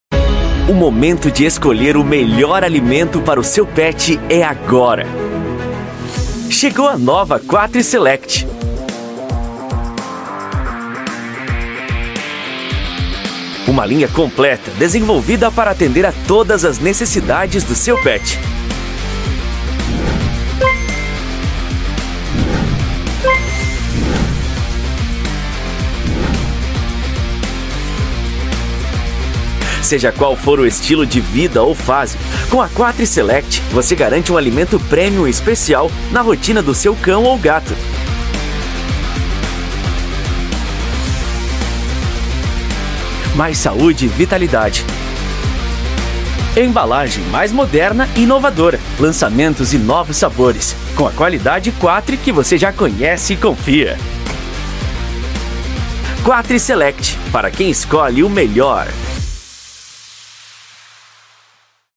INSTITUCIONAL 01: